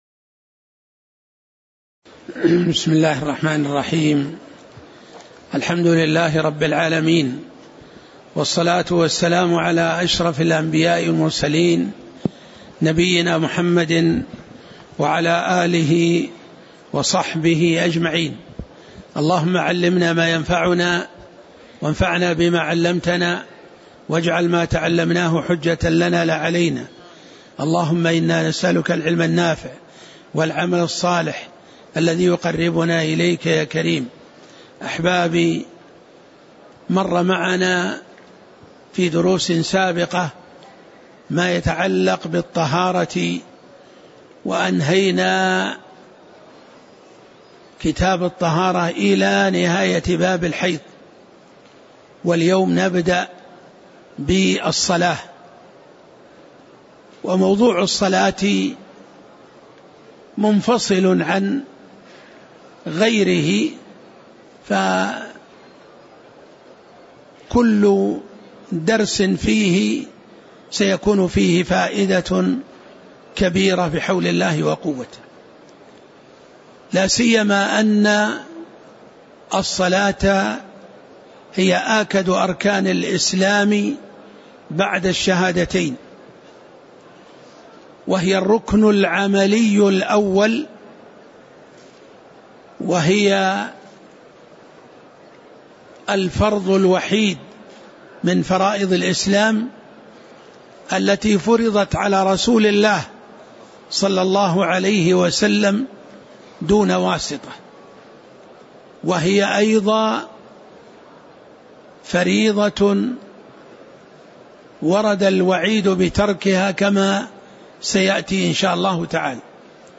تاريخ النشر ١١ صفر ١٤٣٨ هـ المكان: المسجد النبوي الشيخ